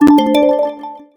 Twinkle